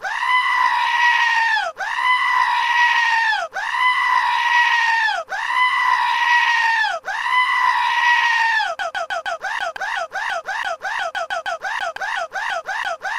Sonnerie mouton.mp3
sonnerie-mouton.mp3